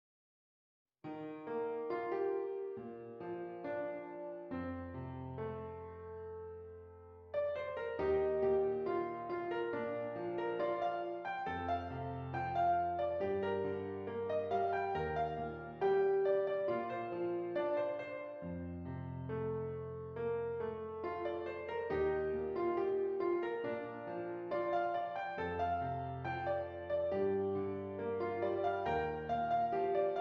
Does Not Contain Lyrics
B flat Major
Andante